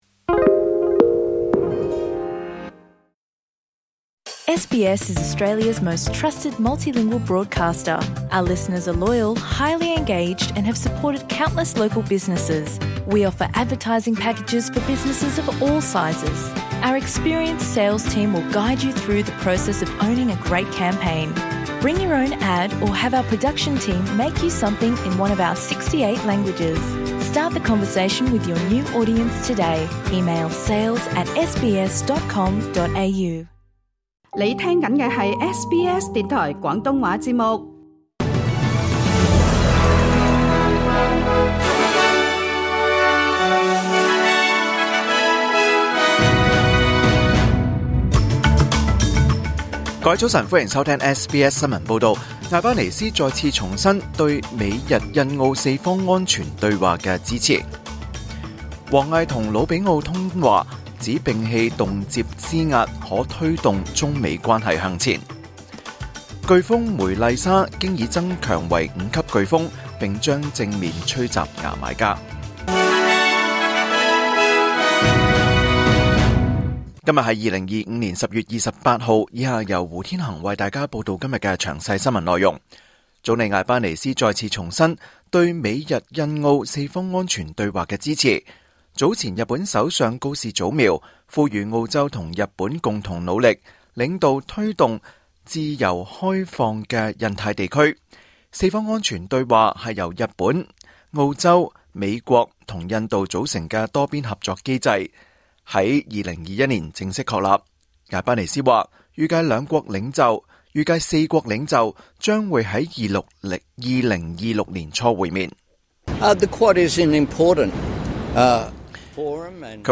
2025年10月28日SBS廣東話節目九點半新聞報道。